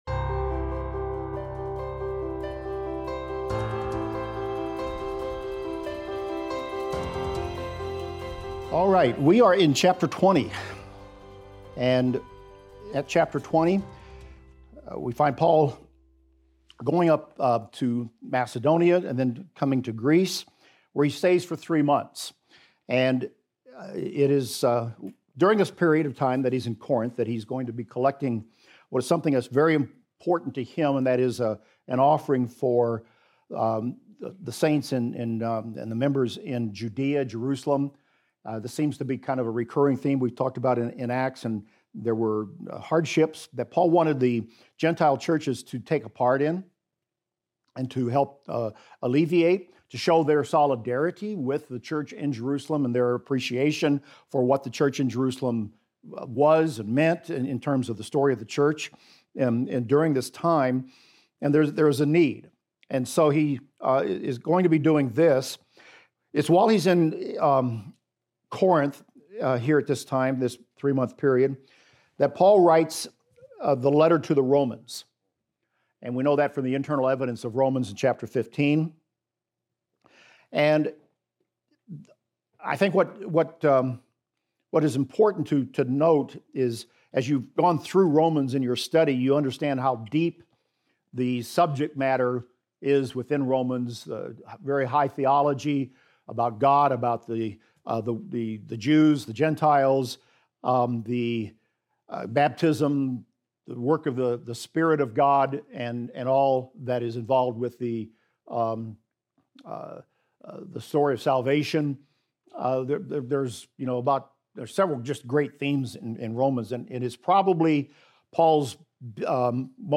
In this class we will discuss Acts 20:3-38 and examine the following: Paul spends three months in Greece, preparing to sail to Syria.